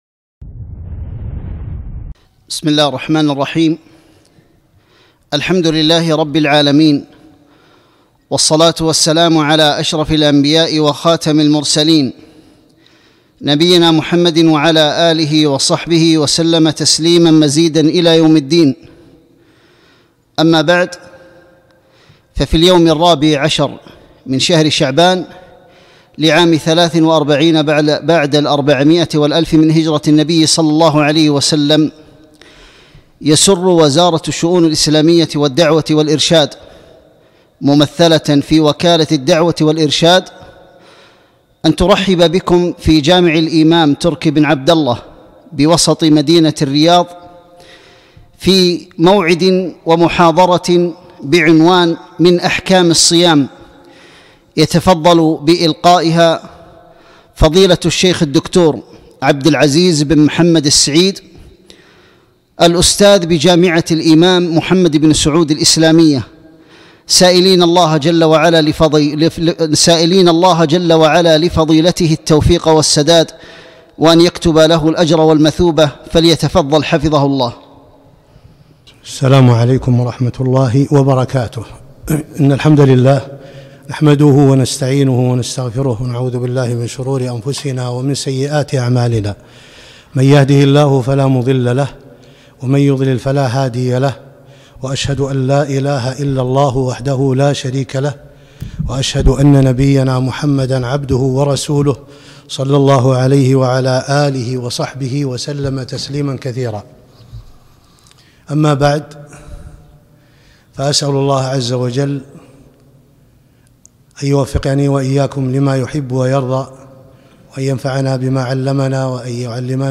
محاضرة - من أحكام الصيام